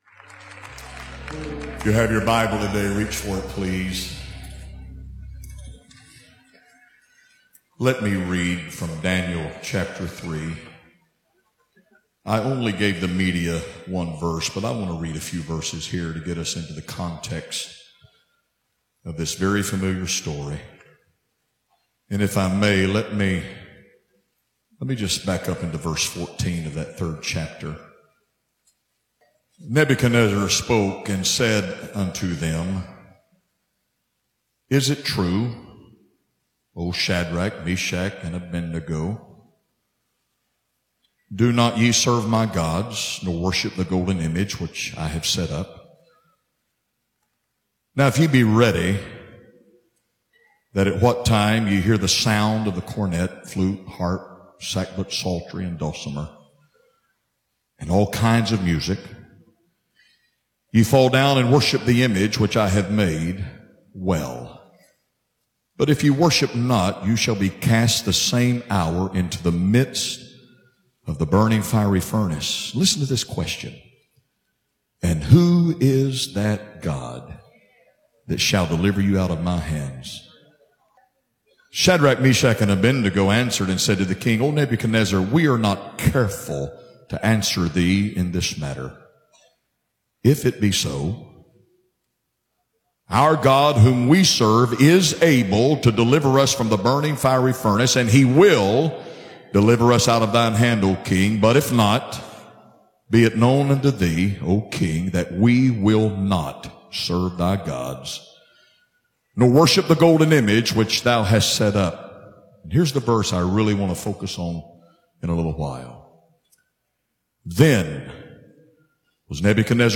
Preaching from Daniel 3:14-19, today at FWC, Bishop Tim Hill, General Overseer of the Church of God preaches on the fiery furnace a believer can find themselves in. For the three Hebrew boys who wouldn't bow to King Nebuchadnezzar, the heat in the furnace was turned seven times hotter.
`` Download sermon: How to Live when the Heat's Turned Up To save the interview right click or press and hold on link and select "Save Link as..." or "Save Target as..."